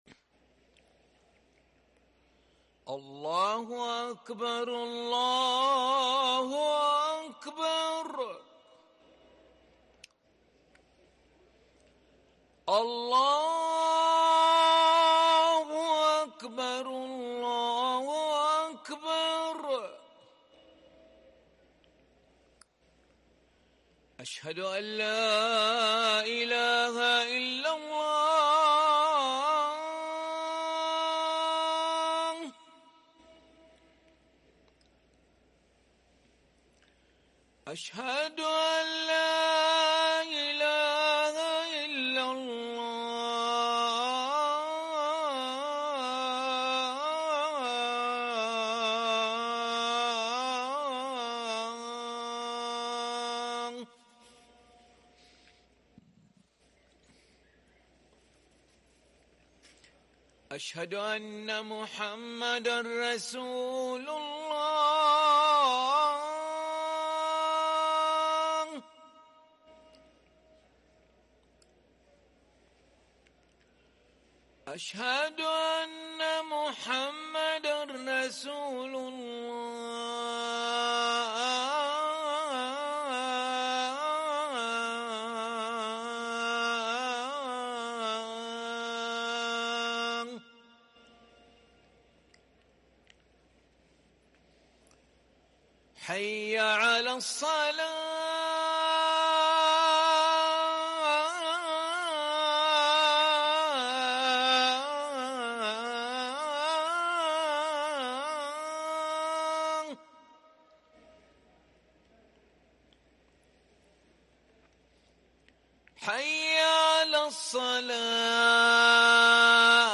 اذان العشاء للمؤذن علي ملا الاحد 9 محرم 1444هـ > ١٤٤٤ 🕋 > ركن الأذان 🕋 > المزيد - تلاوات الحرمين